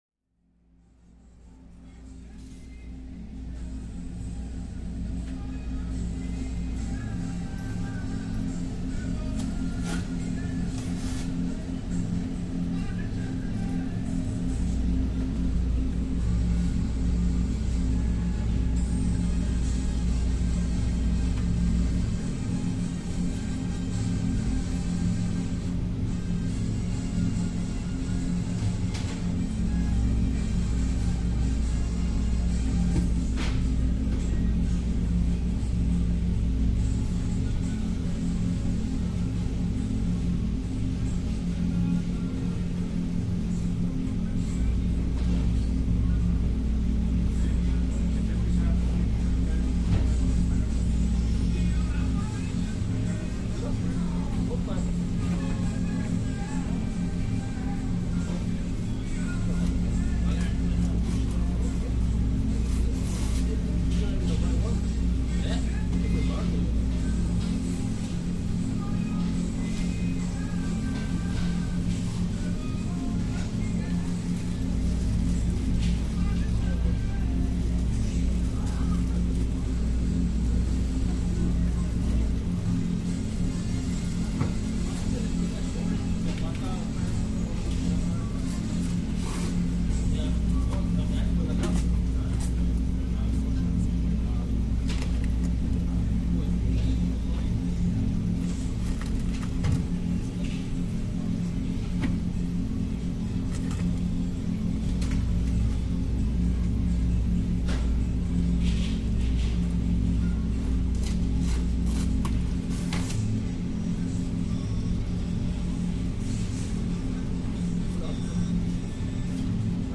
Droning Texan refrigerators